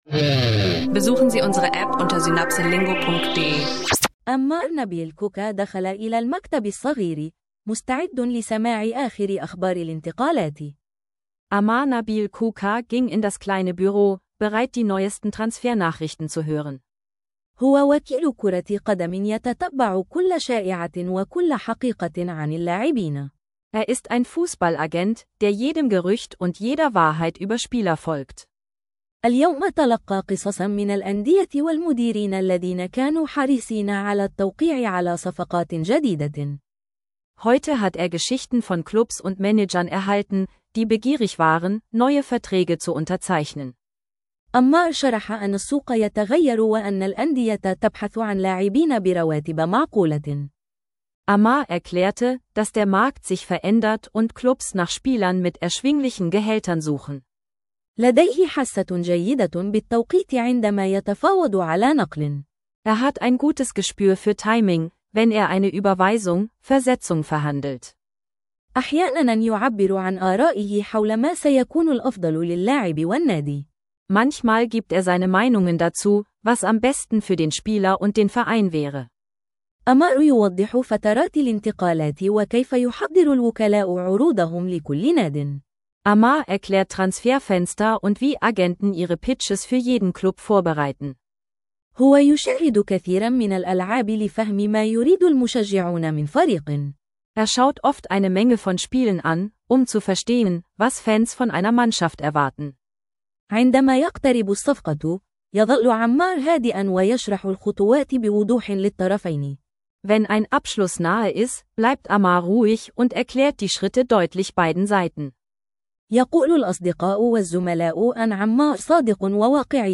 Arabisch lernen durch eine einfache Fußballstory: Transfermeldungen, rühiges Erzählen und klare Phrasen – perfekt fürs Arabisch lernen online.